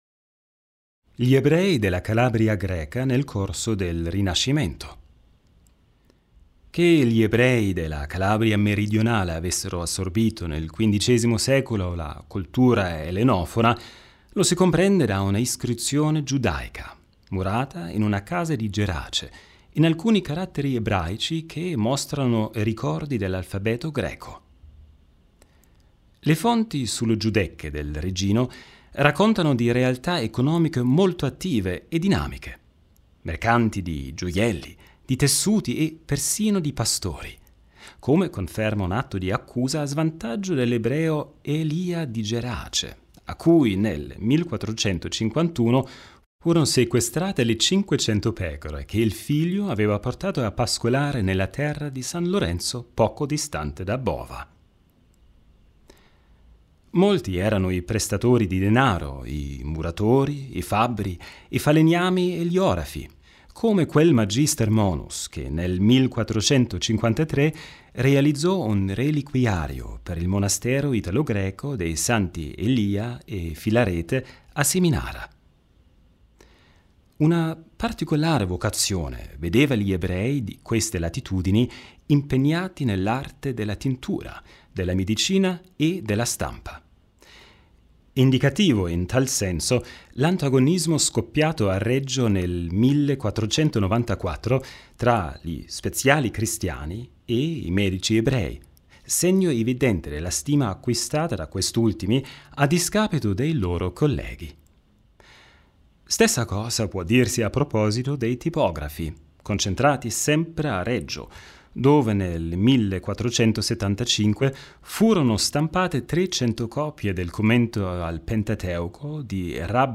HOME AUDIOGUIDA DELLA GIUDECCA